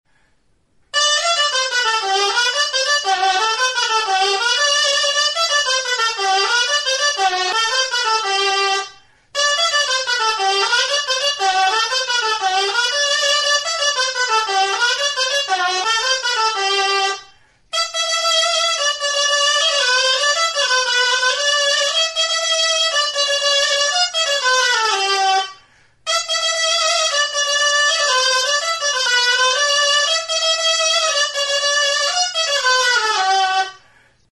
Aerófonos -> Lengüetas -> Doble (oboe)
Grabado con este instrumento.
Do tonuan afinatua dago.